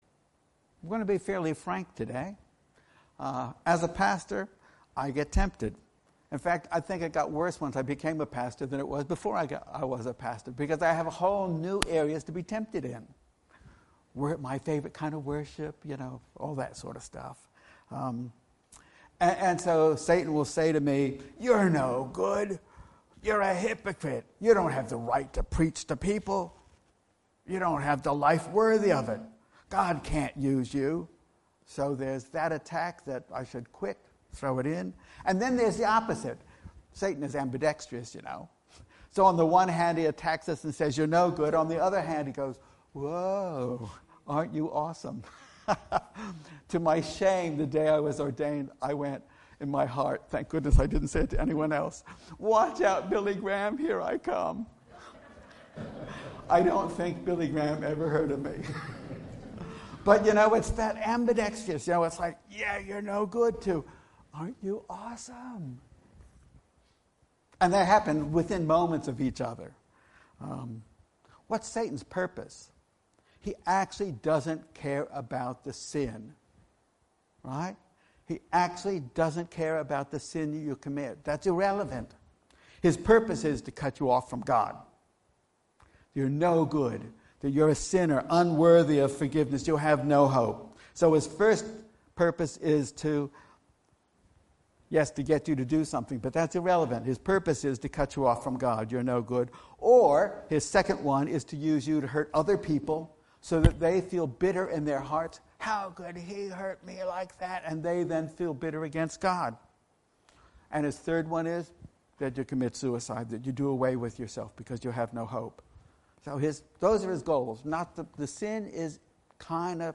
Sermons Online Audio